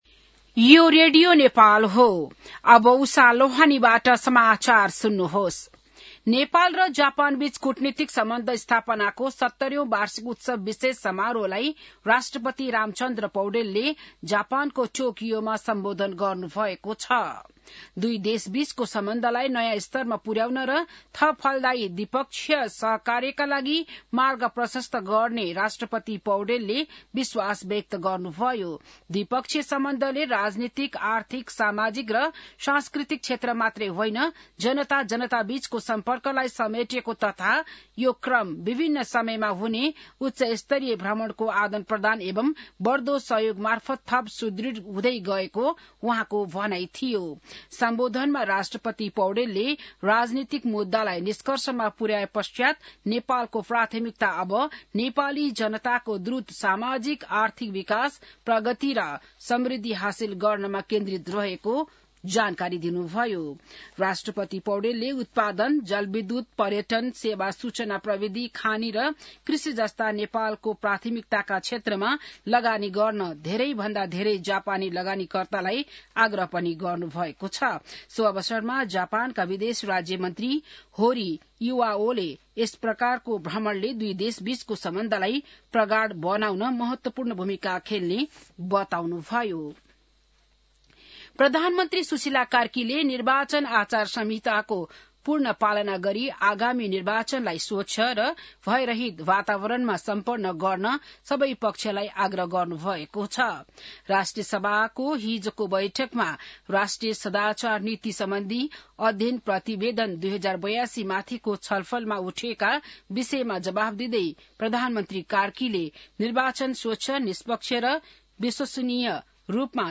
बिहान १० बजेको नेपाली समाचार : २० माघ , २०८२